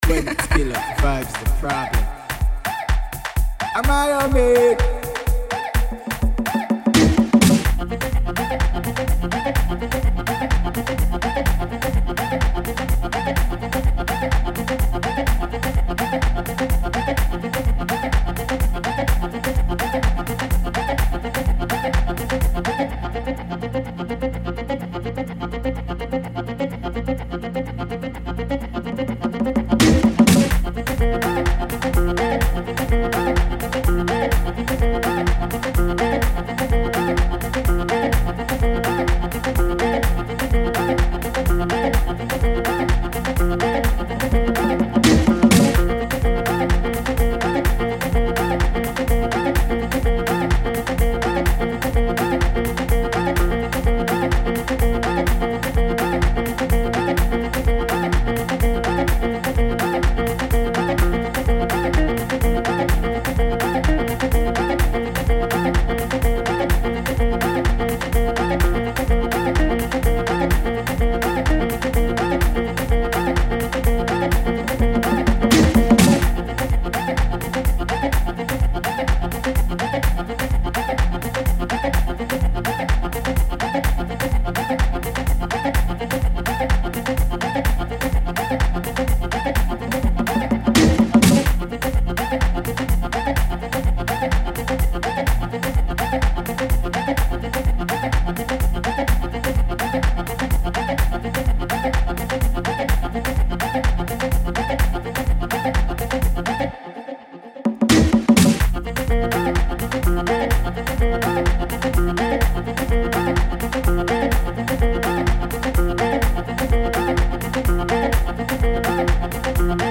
afro dance tunes